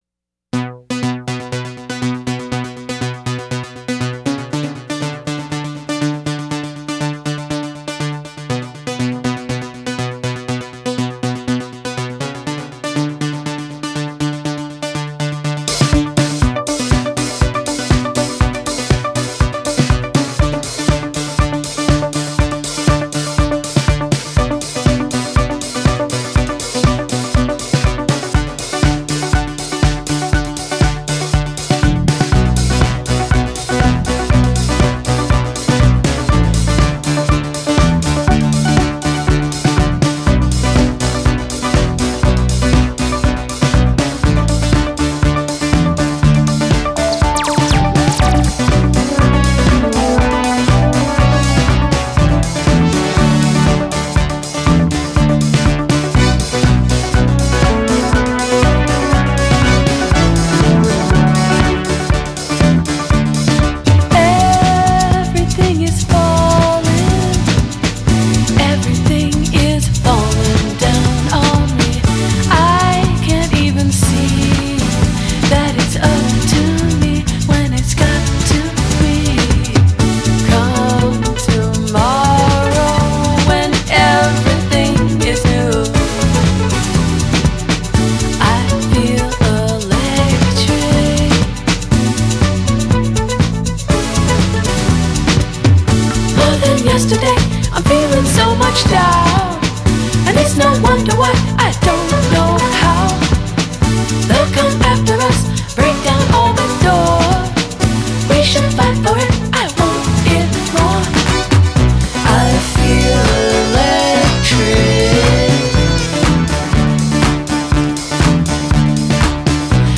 voce e tastiere